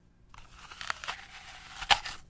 Звуки яблока
Хруст кусания яблока